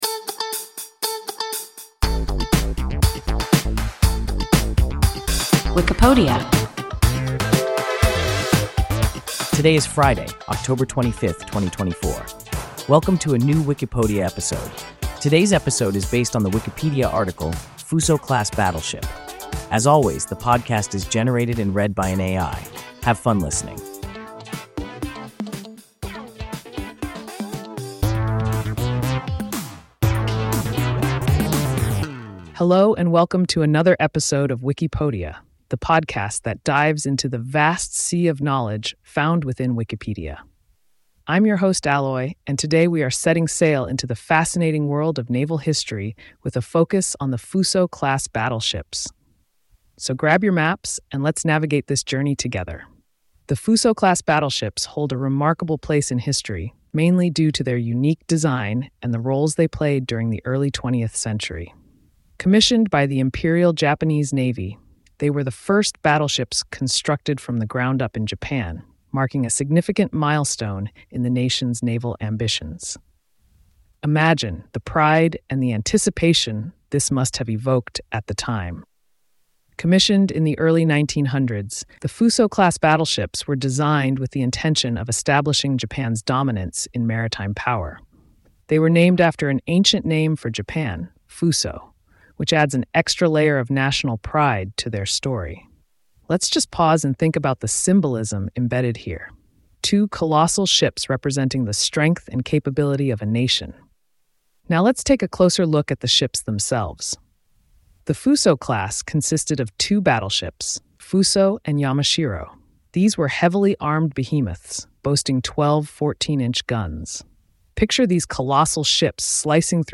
Fusō-class battleship – WIKIPODIA – ein KI Podcast